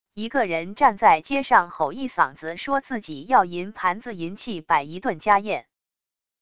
一个人站在街上吼一嗓子说自己要银盘子银器摆一顿家宴wav音频